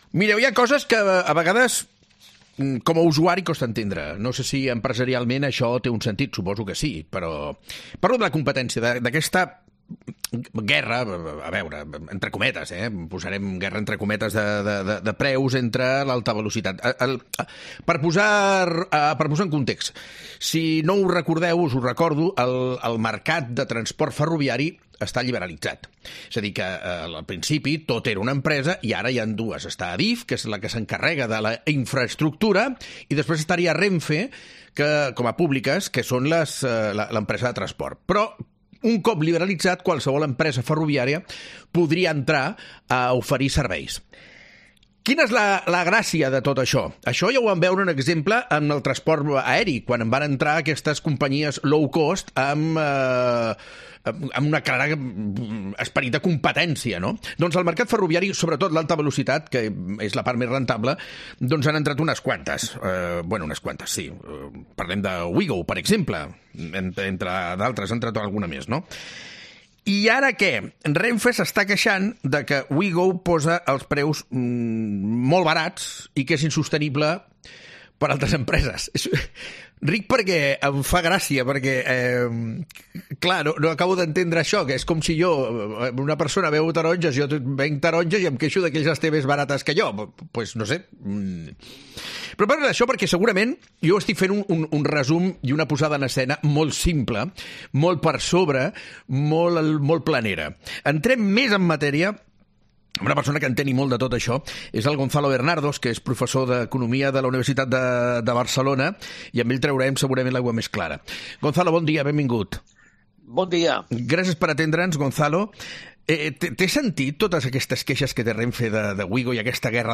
En una entrevista reveladora con Gonzalo Bernardos, profesor de economía de la Universidad de Barcelona , se exploraron las complejidades del conflicto.